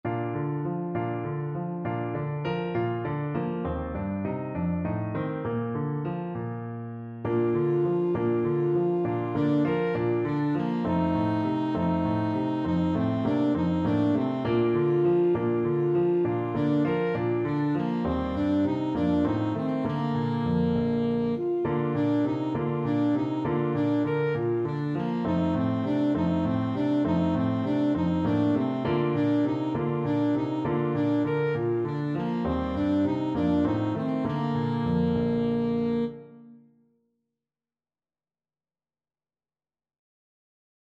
Alto Saxophone
6/8 (View more 6/8 Music)
Moderato
Bb4-Bb5